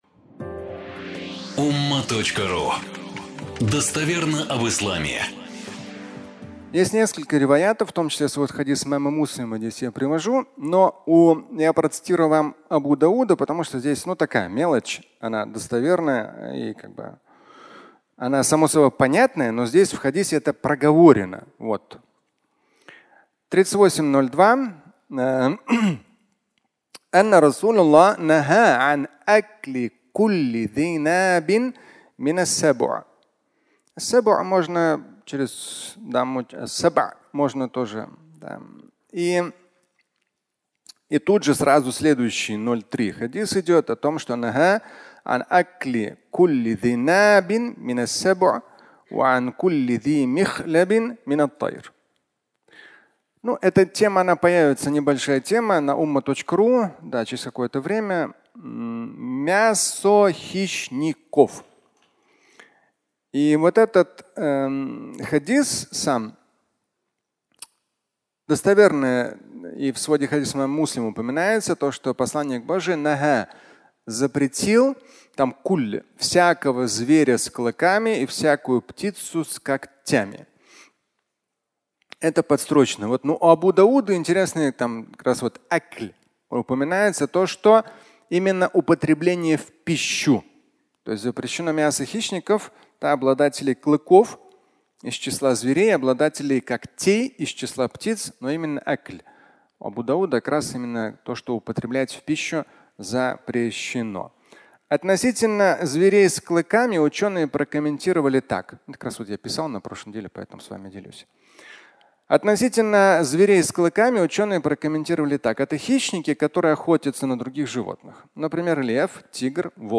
Мясо хищника (аудиолекция)